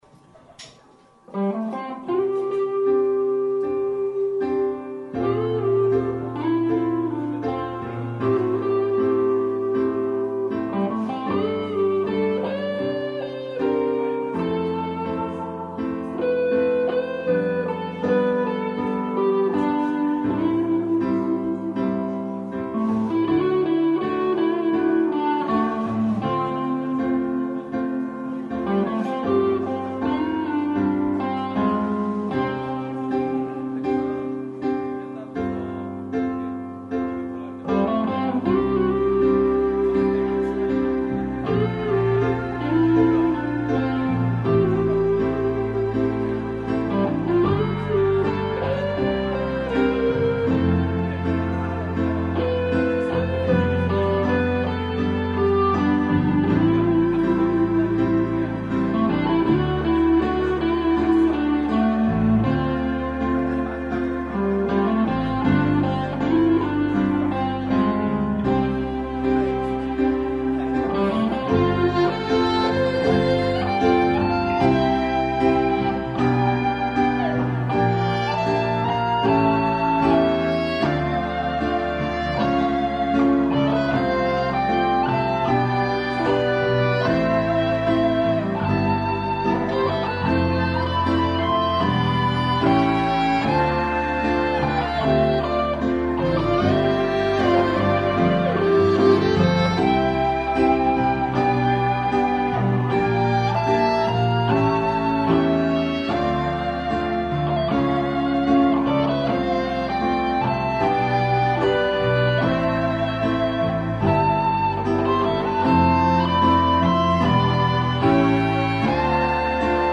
색소폰하시는 분 정말 잘하시네요 ㅠ 전 언제 저렇게 할지.